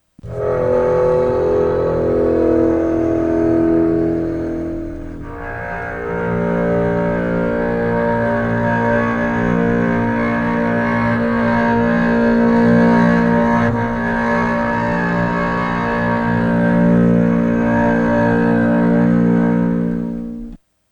Source: Sul pont (E/B flat) (9:33-12:40)
Processing: stereo = 511 + KS = 363/257 (E/B flat), F = 1013, raise input -> 10, then 20, 30, 40, 50, 40, 30, 20, 0
Sul_Pont.aiff